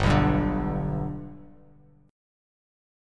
G Chord
Listen to G strummed